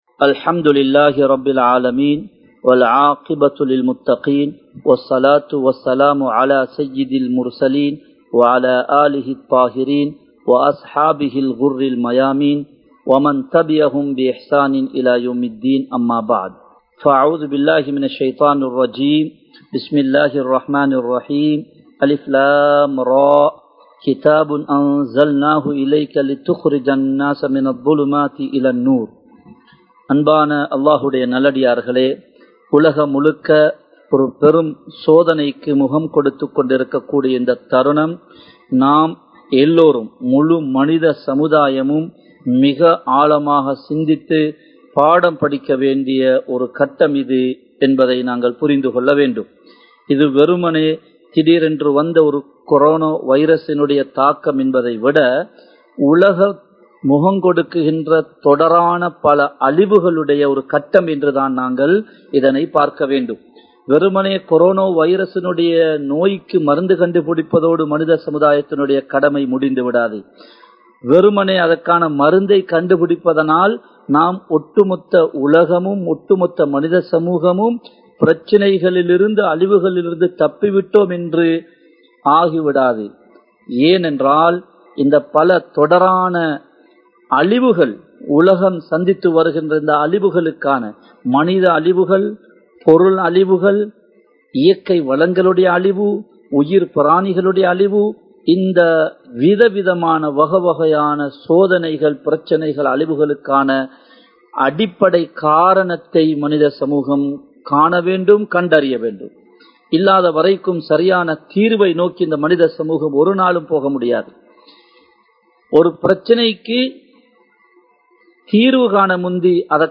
Soathanaihalukkaana Kaaranagal Evai? (சோதனைகளுக்கான காரணங்கள் எவை?) | Audio Bayans | All Ceylon Muslim Youth Community | Addalaichenai
Live Stream